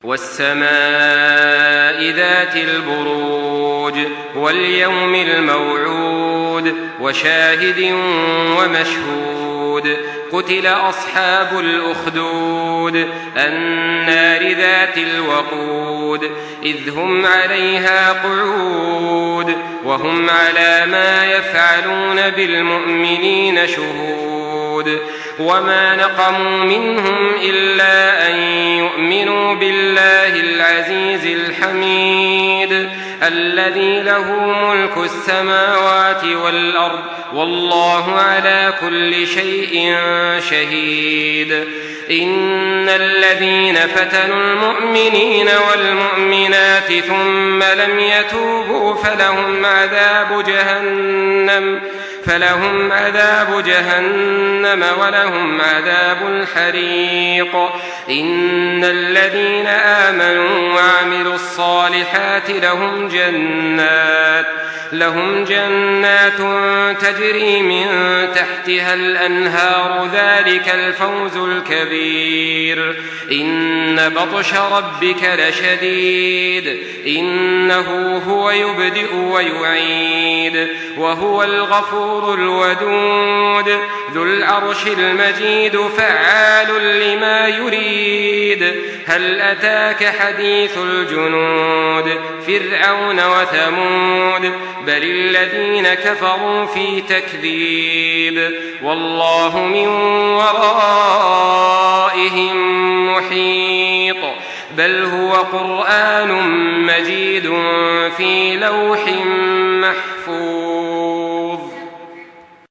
Surah Al-Buruj MP3 in the Voice of Makkah Taraweeh 1424 in Hafs Narration
Listen and download the full recitation in MP3 format via direct and fast links in multiple qualities to your mobile phone.